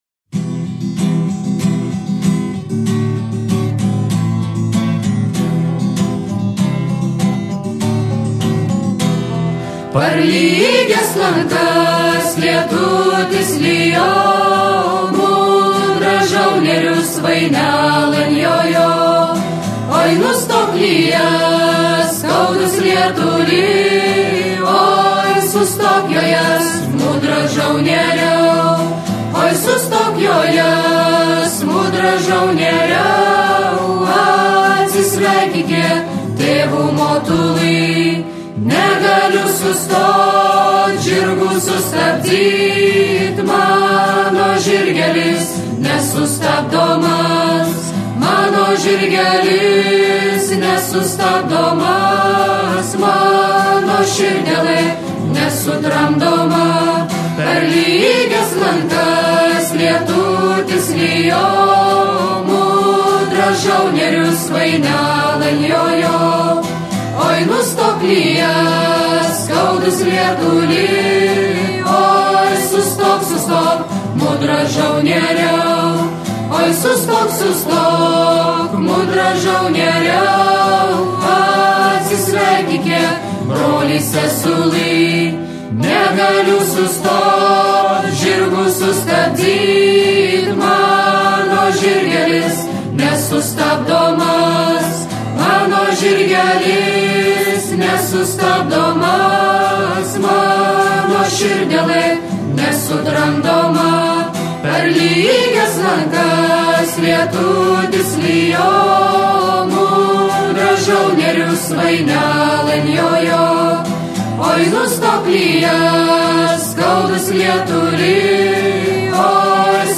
karinė-istorinė
ansamblis su gitara